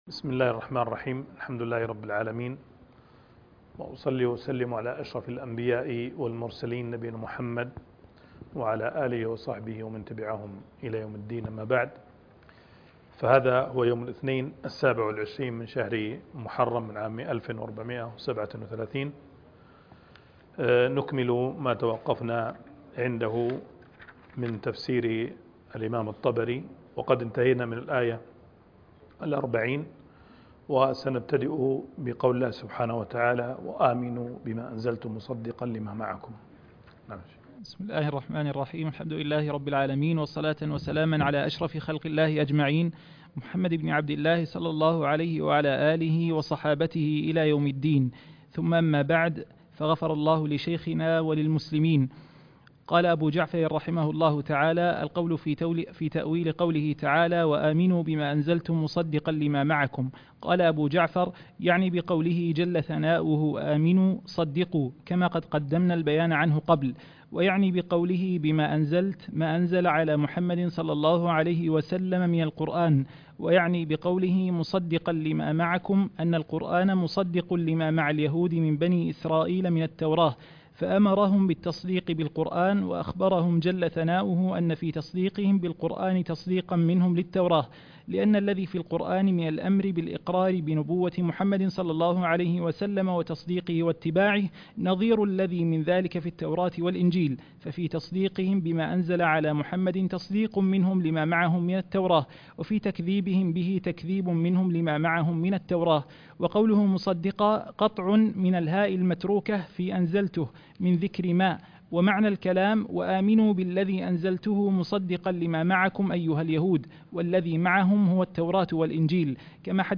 التعليق على تفسير الطبري الدرس -51-